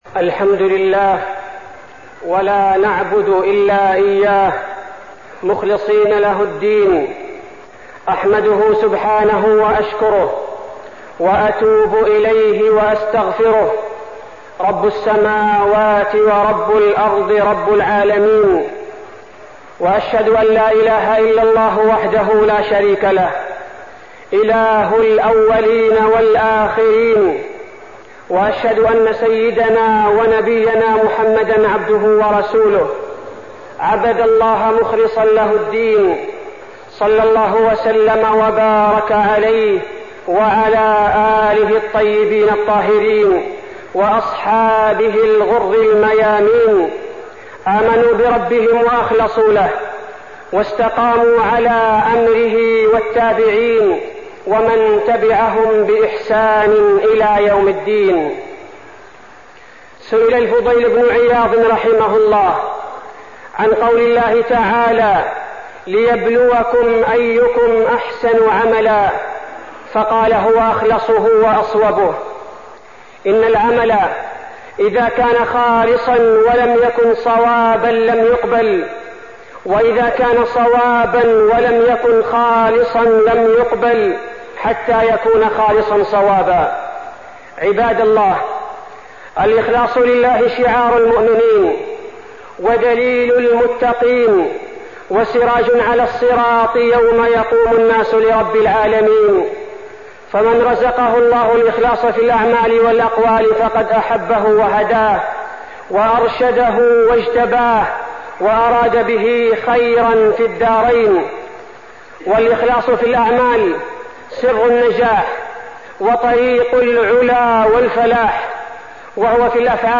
خطبة الإخلاص لله وفيها: شروط قبول الأعمال الصالحة، وأن الإخلاص شرط هام من شروط التوبة، واللذات إذا صاحبتها نيتها صالحة يكون له فيها أجر
تاريخ النشر ٢٣ جمادى الأولى ١٤٢٣ المكان: المسجد النبوي الشيخ: فضيلة الشيخ عبدالباري الثبيتي فضيلة الشيخ عبدالباري الثبيتي الإخلاص لله